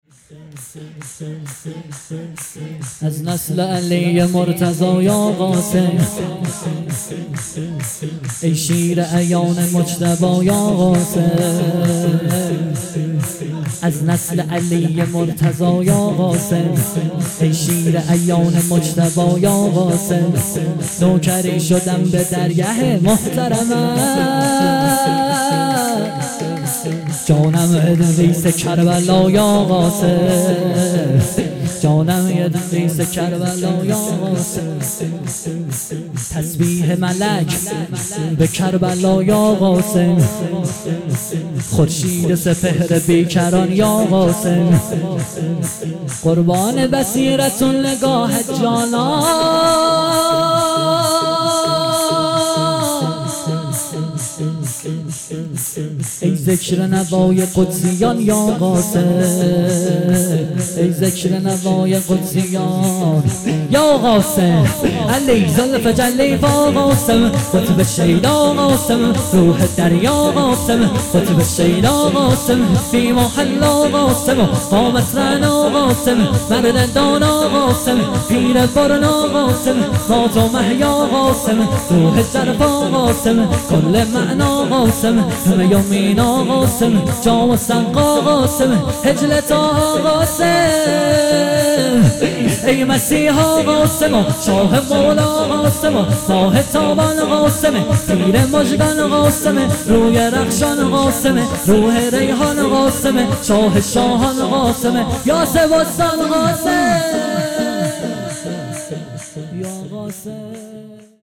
ولادت پیامبر۹۹